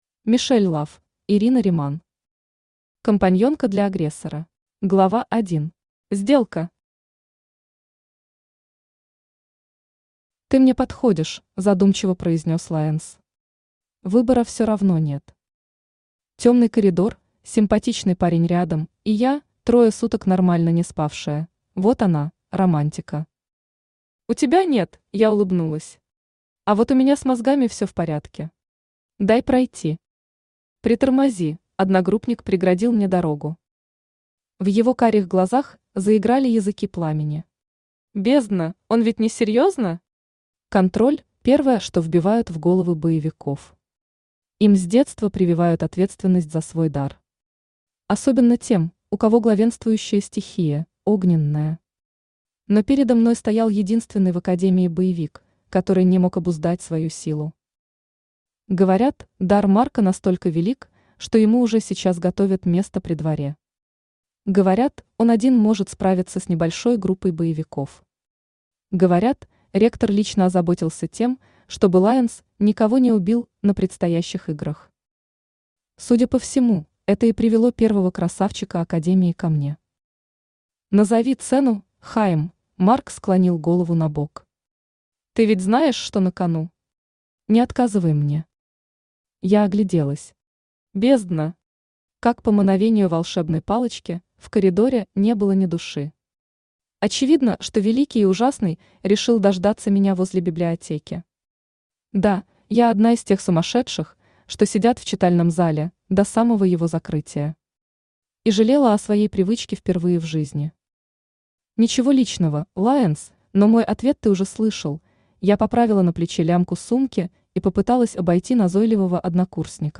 Аудиокнига Компаньонка для Агрессора | Библиотека аудиокниг
Aудиокнига Компаньонка для Агрессора Автор Мишель Лафф Читает аудиокнигу Авточтец ЛитРес.